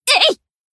BA_V_Aru_Battle_Shout_3.ogg